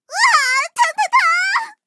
M4谢尔曼小破语音1.OGG